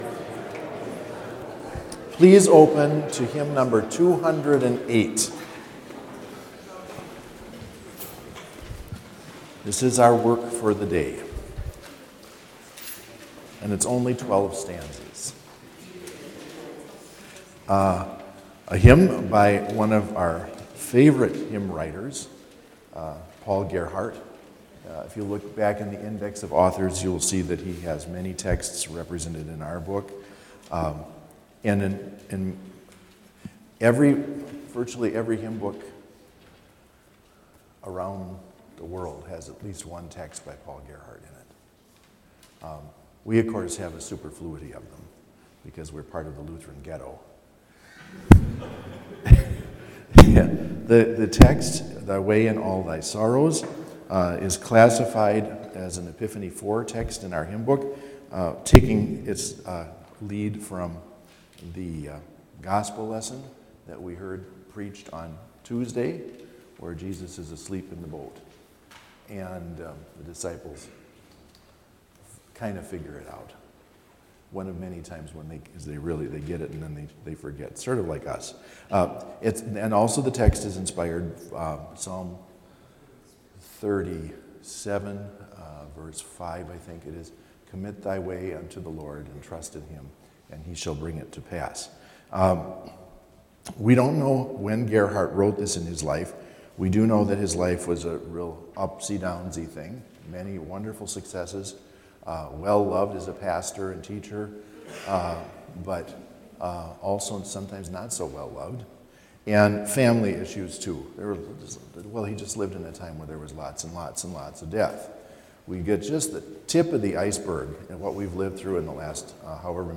Complete service audio for Chapel - February 3, 2022